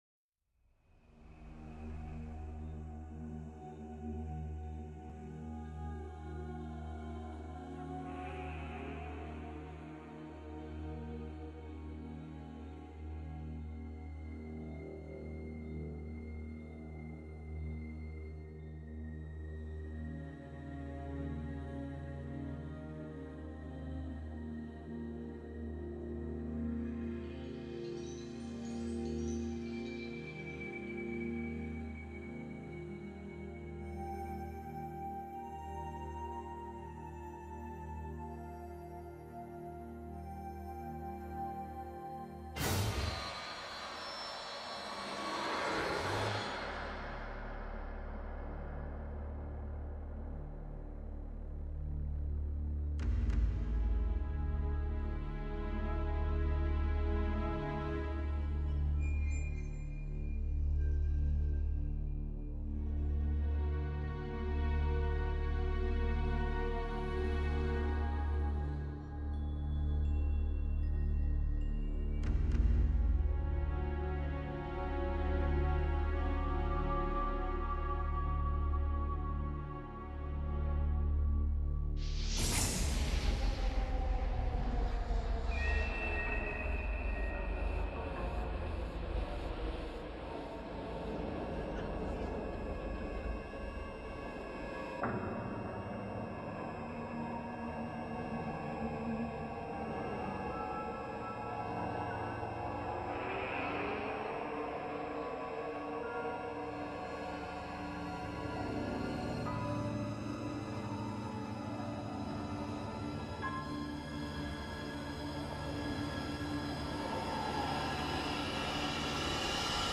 Original Score